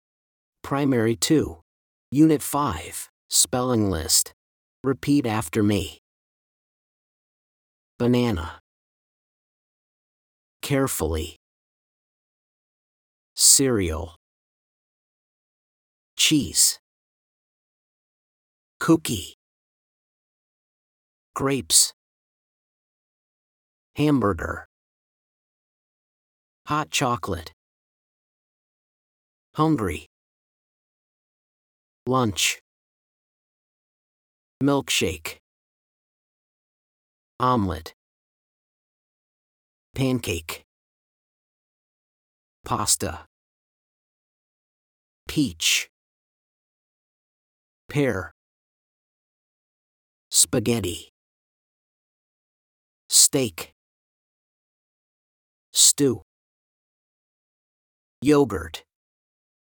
SPELLING LIST FOR UNIT 5
blankThese are the words on the spelling list. Listen and repeat after the teacher:
spellinglist_p2_unit5.mp3